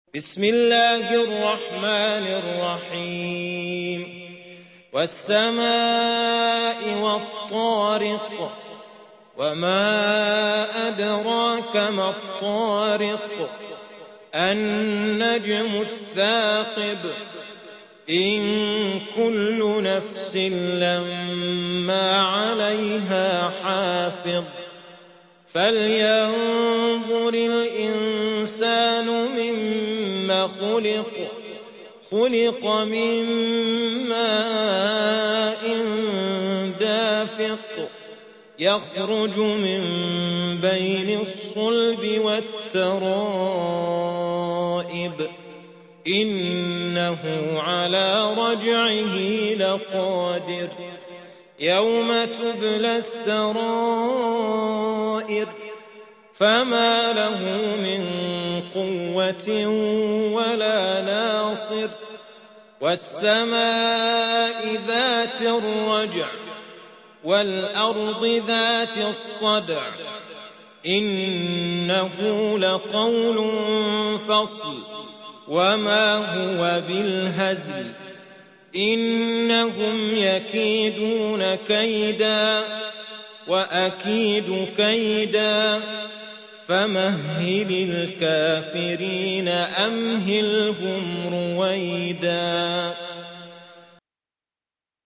تحميل : 86. سورة الطارق / القارئ عبد الهادي كناكري / القرآن الكريم / موقع يا حسين